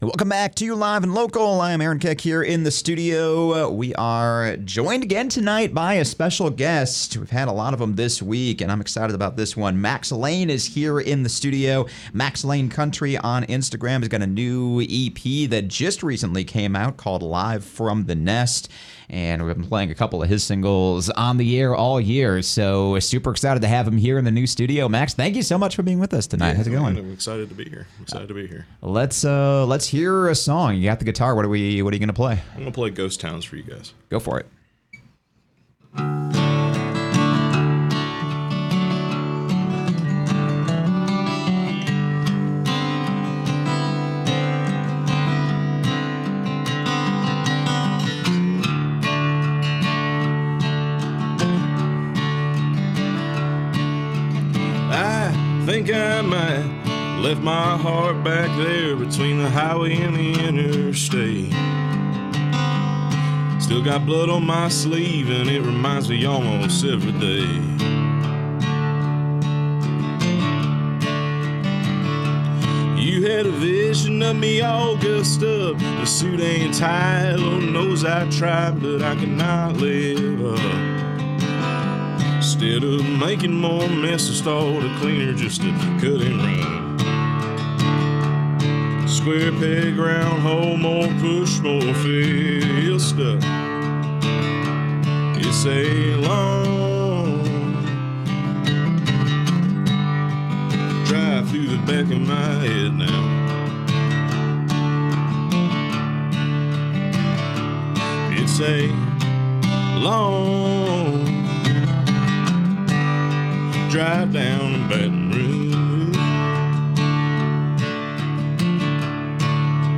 Local singer/songwriter
is vintage country
deep baritone delivering introspective lyrics